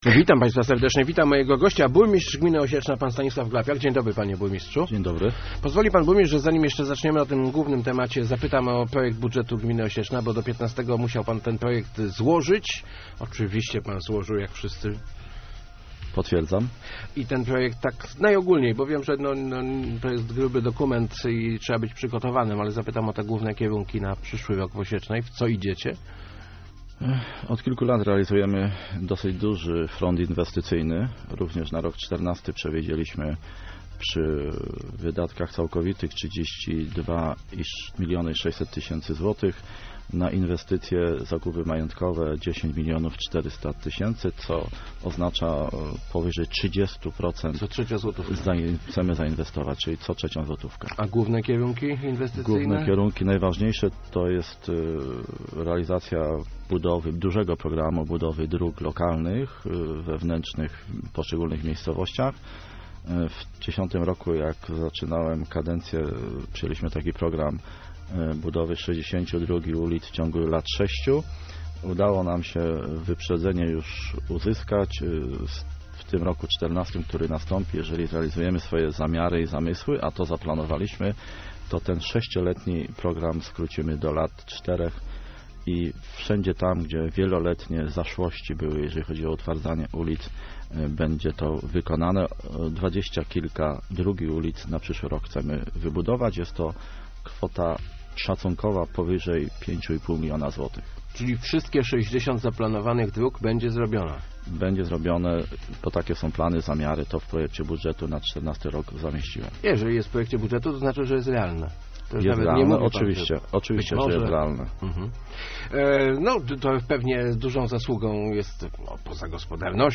Od 1 stycznia przyszłego roku gmina Osieczna będzie sama prowadziła gospodarkę odpadami. Zmiany nie będą duże - zapewniał w Rozmowach Elki burmistrz Stanisław Glapiak.